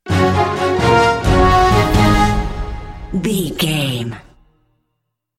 Ionian/Major
A♭
horns
drums
electric guitar
synthesiser
orchestral
orchestral hybrid
dubstep
aggressive
energetic
intense
synth effects
wobbles
driving drum beat
epic